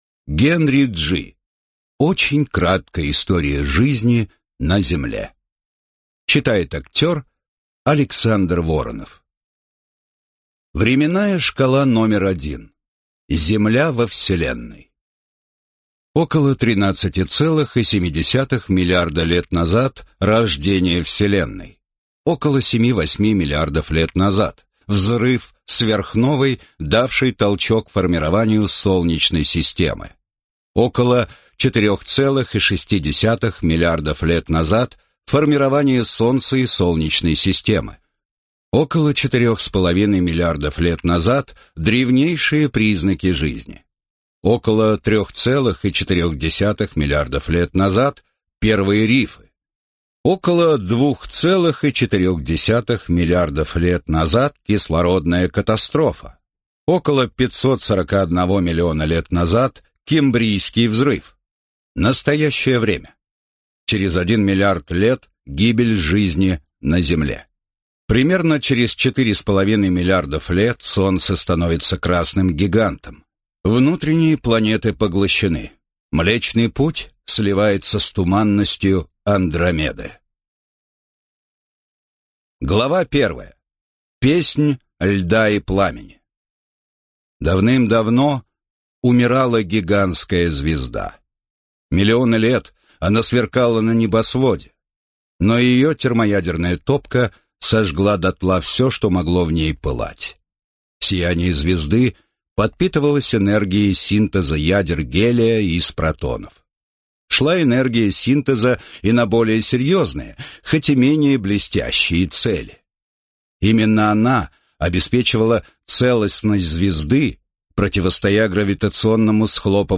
Аудиокнига Очень краткая история жизни на Земле: 4,6 миллиарда лет в 12 лаконичных главах | Библиотека аудиокниг